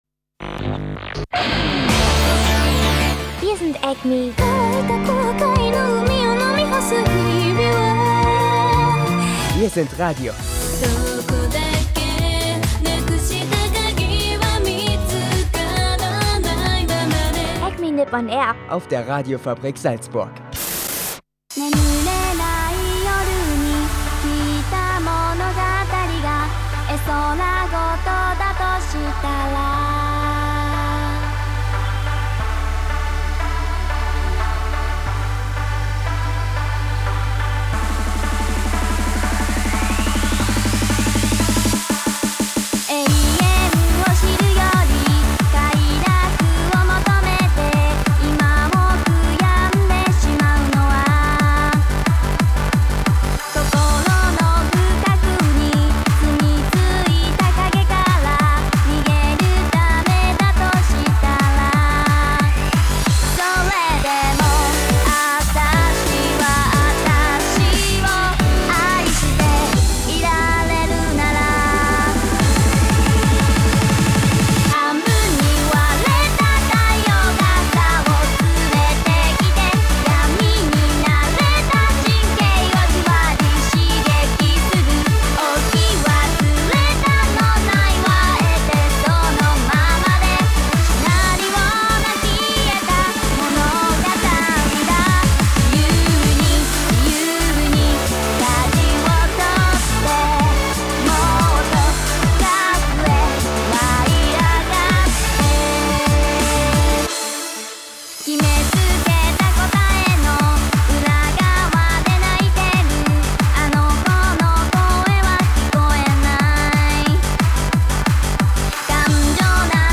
Es ist heiß in der Stadt und im Sender und es gibt dazu passende Musik.